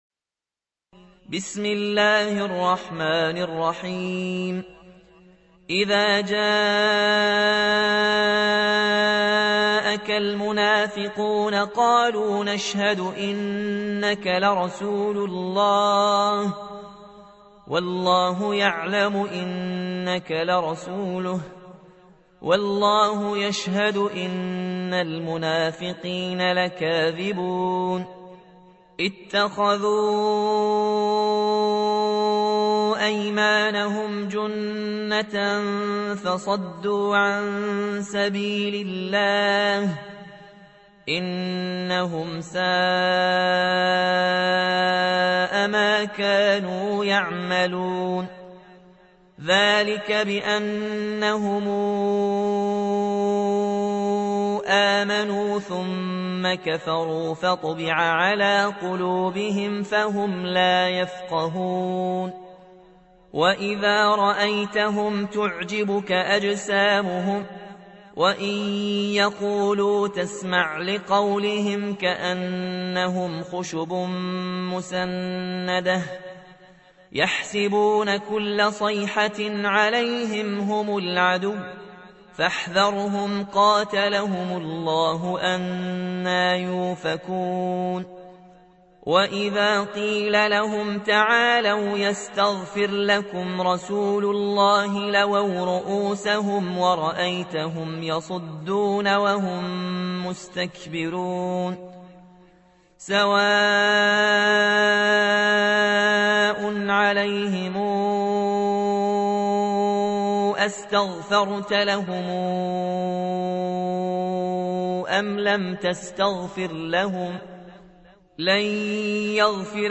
سورة المنافقون مدنية عدد الآيات:11 مكتوبة بخط عثماني كبير واضح من المصحف الشريف مع التفسير والتلاوة بصوت مشاهير القراء من موقع القرآن الكريم إسلام أون لاين